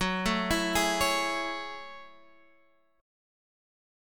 F# Minor 9th